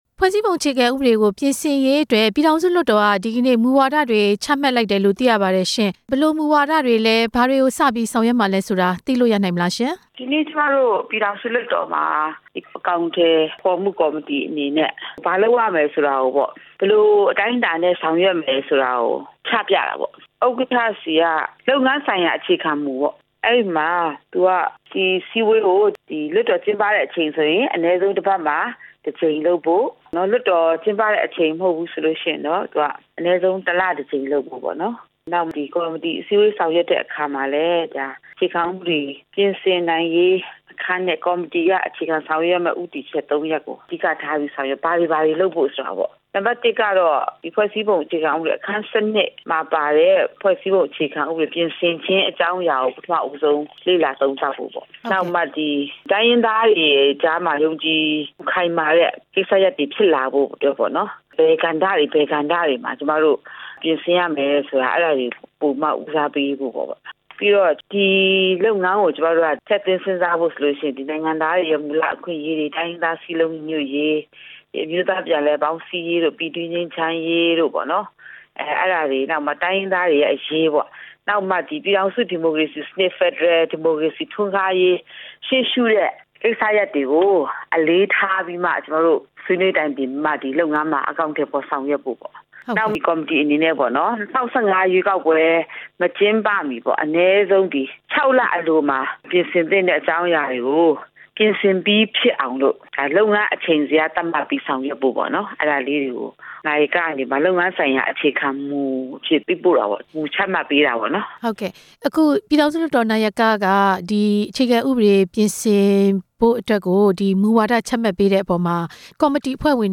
လွှတ်တော်ကိုယ်စားလှယ် ဒေါ်ဘွဲ့ဒူနဲ့ မေးမြန်းချက်